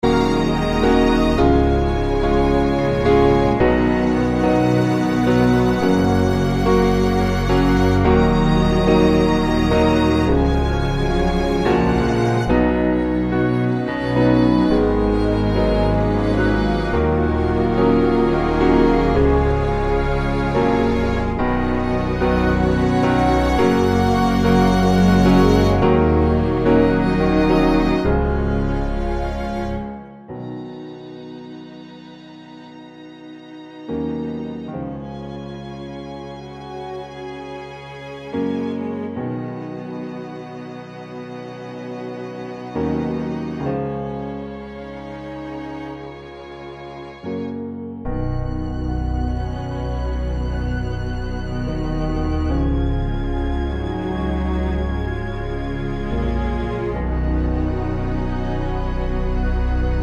Acoustic Version Pop (2010s) 3:57 Buy £1.50